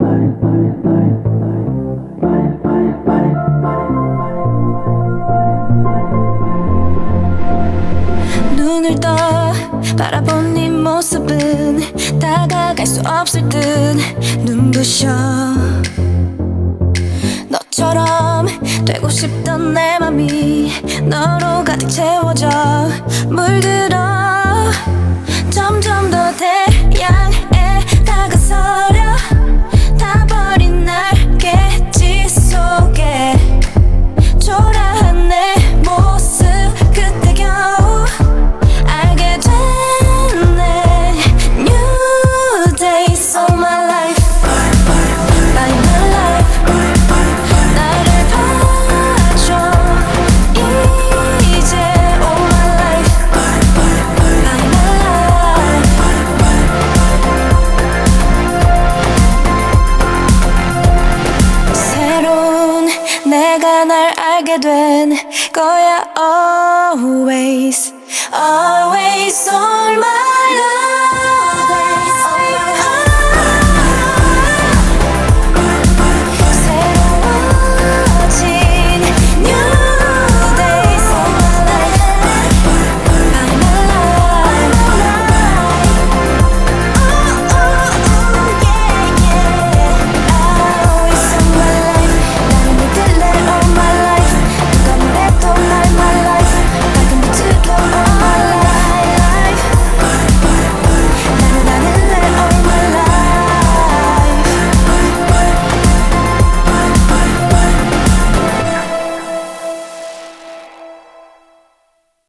BPM108
Audio QualityMusic Cut
A nice synthpop song.